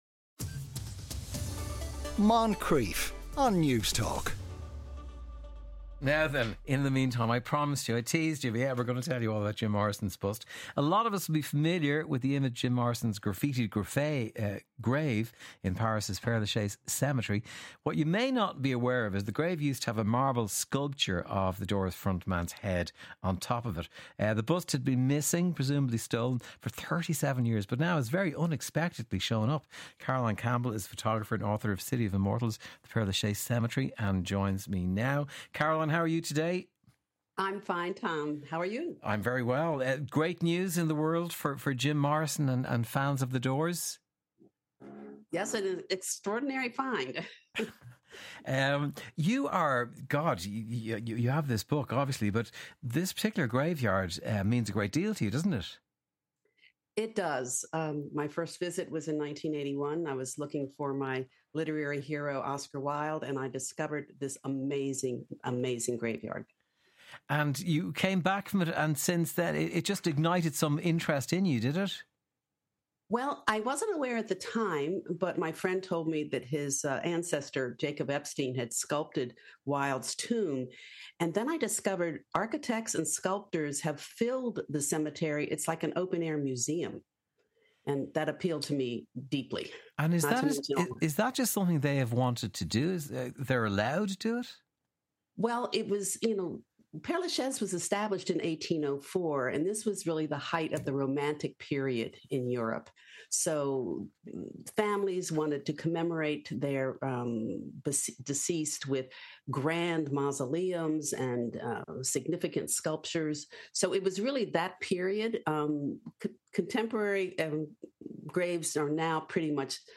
Moncrieff Show interview